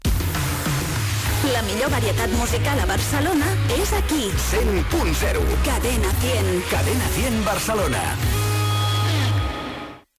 Identificació de l'emissora a Barcelona i freqüència.
FM